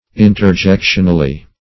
Search Result for " interjectionally" : The Collaborative International Dictionary of English v.0.48: Interjectionally \In`ter*jec"tion*al*ly\, adv.
interjectionally.mp3